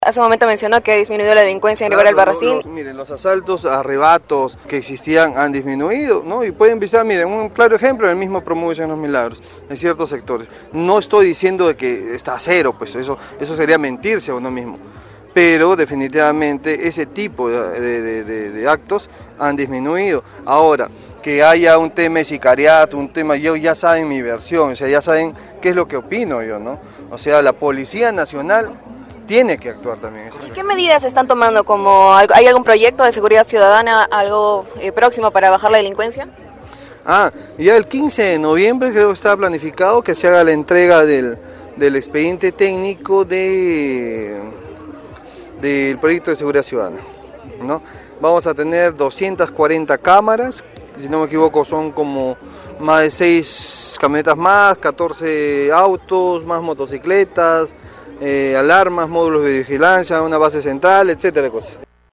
La autoridad brindó declaraciones al finalizar la presentación de la tarjeta Vecino Puntual Albarracino (VPGAL), que premia a la población que puntualmente ha cumplido con pagar sus arbitrios y autovalúo hasta el 31 de agosto, con hasta un 30 % de descuento en locales como restaurantes, pollerías, cevicherías, gimnasios, clínicas dentales, veterinarias, cafeterías, hoteles, mueblerías, barberías y más, locales ubicados en nuestro distrito.